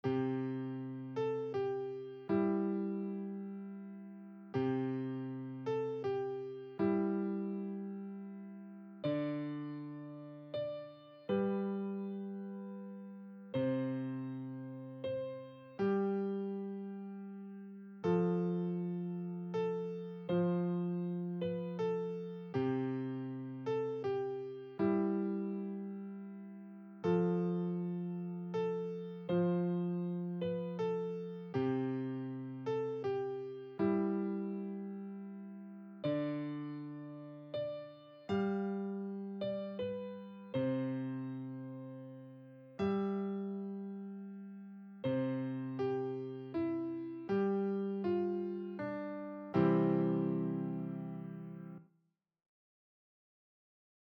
Douce Nuit - Piano Débutant 80bpm.mp3